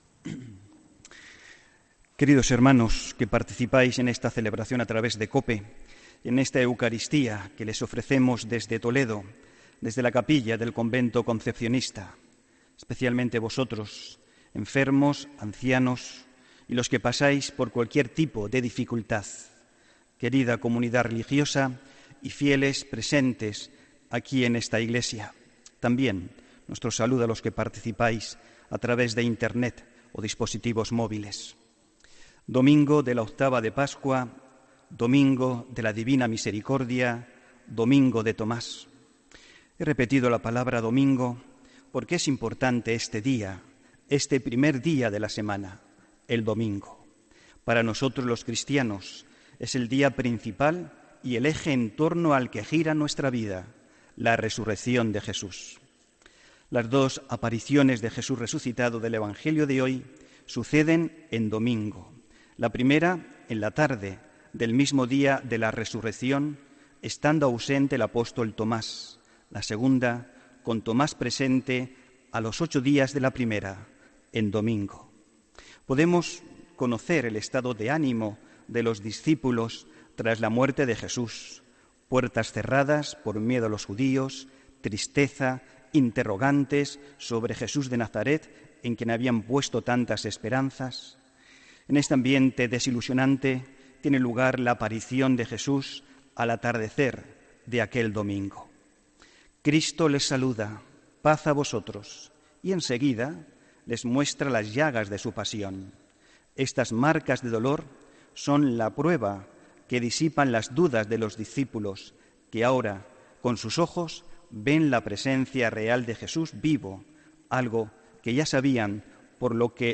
Homilía del domingo 23 de abril